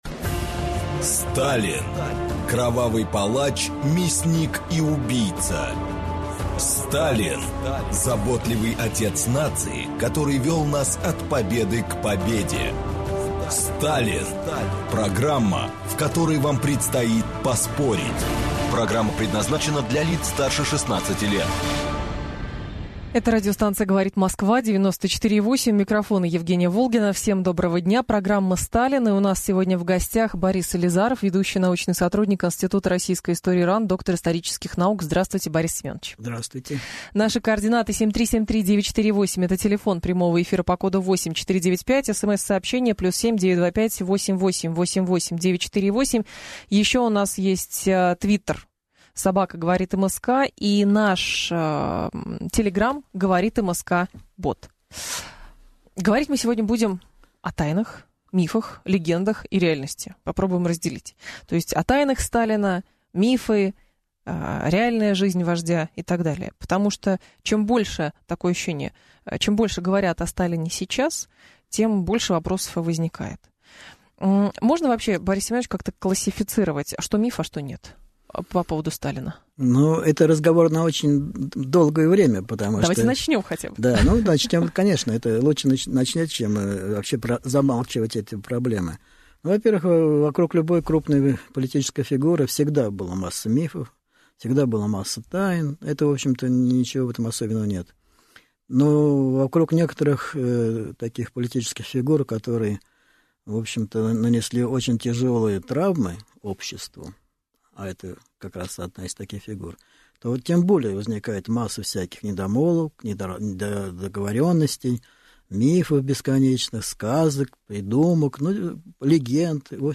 Аудиокнига Тайны Сталина. Мифы и реальность жизни вождя | Библиотека аудиокниг